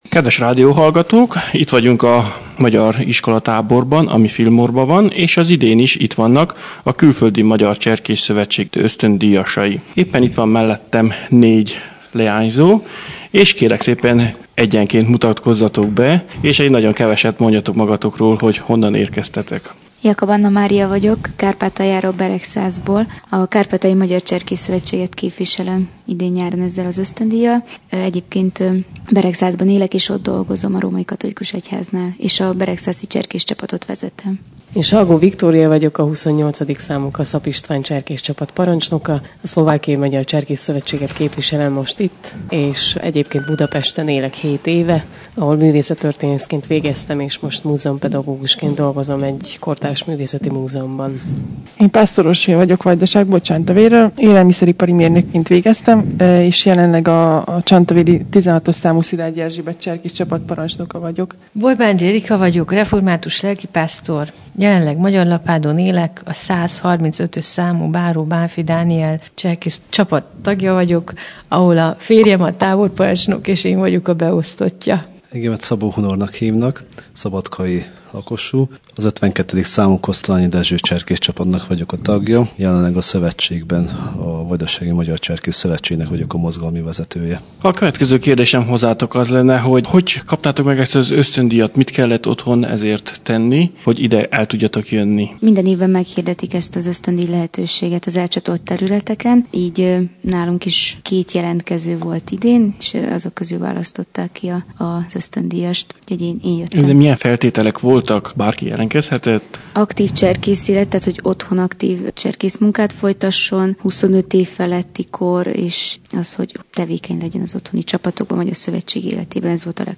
Alkalmam adódott, hogy én is részt vegyek ebben a táborban, megragadtam a lehetőséget és egy interjút készítettem az ösztöndíjasokkal. Az interjú alatt kértem, hogy mutatkozzanak be, mutassák be a küldő cserkész szövetségüket és beszéljenek azon élményeikről melyekben részük volt az iskolatáborban. https